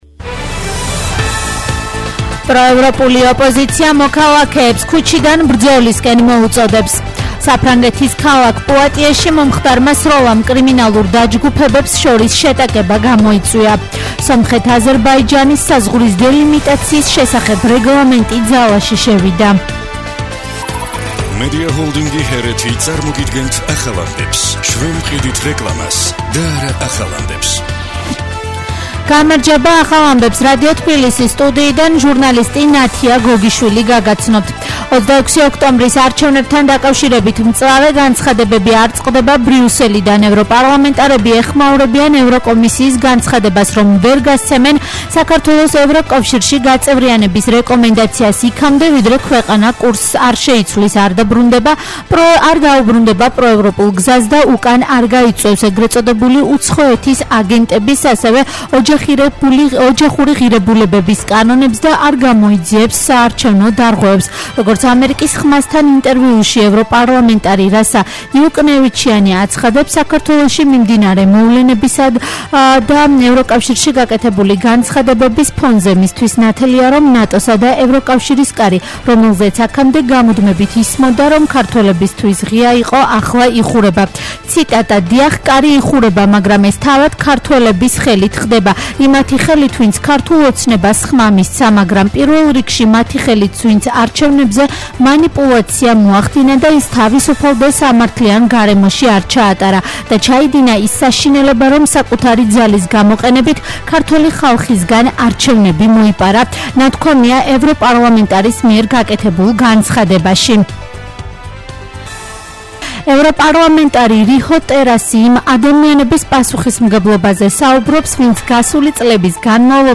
ახალი ამბები